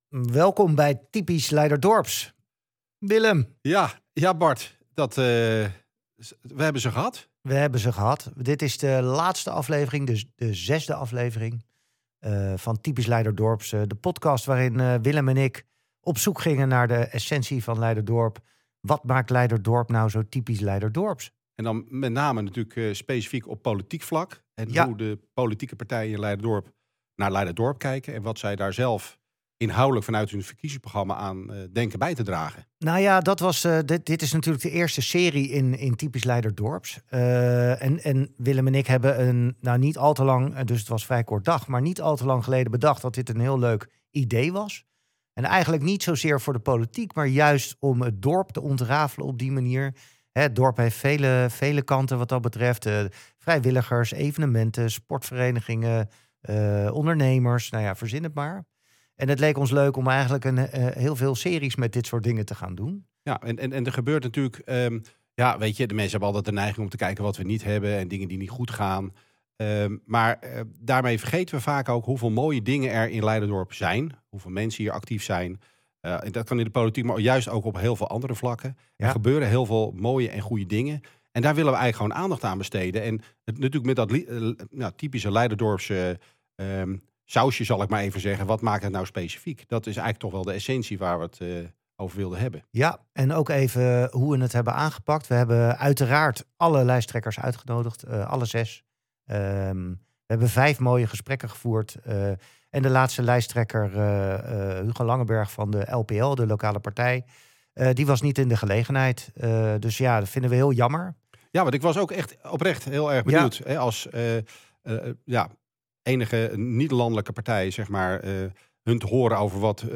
De podcast van twee opgewekte, ondernemende Leiderdorpers met hart voor het dorp.